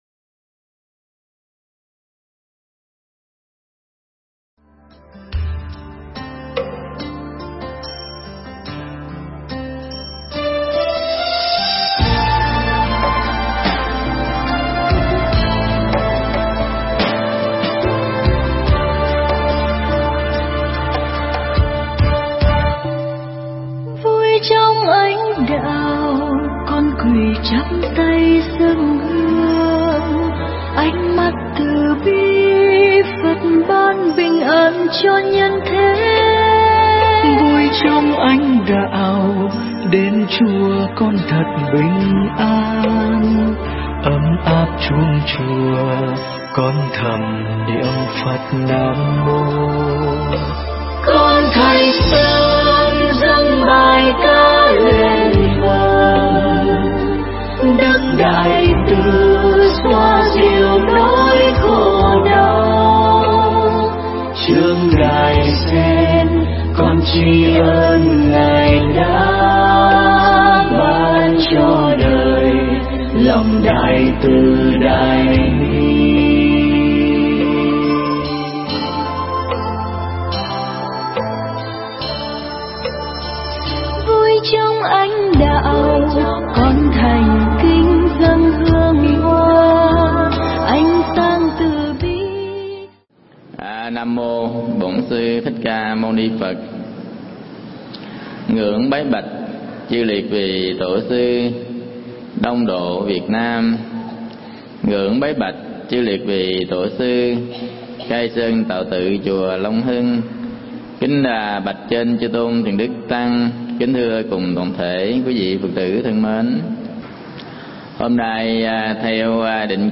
Mp3 Thuyết Giảng Ba Dạng Tu Vãng Sanh
giảng tại đạo tràng Chùa Long Hưng, Quận Tân Bình